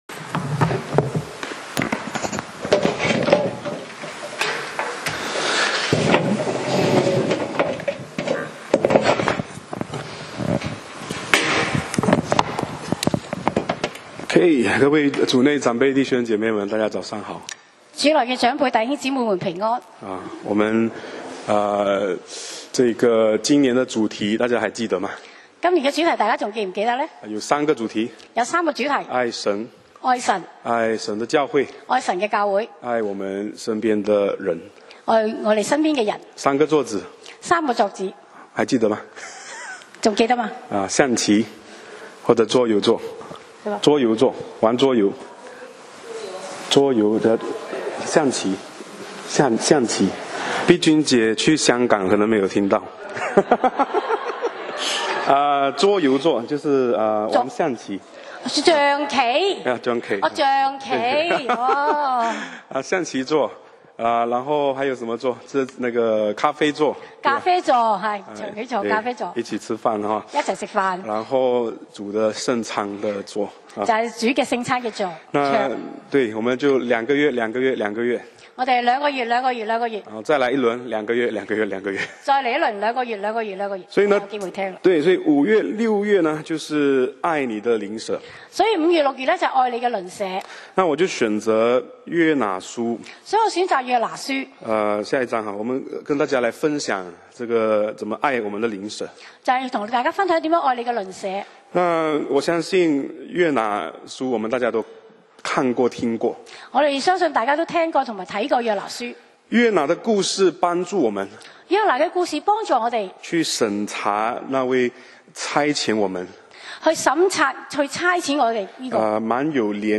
講道 Sermon 題目 Topic：躲避上帝 經文 Verses：约拿书 Jonah 1. 1耶和华的话临到亚米太的儿子约拿，说： 2 “起来！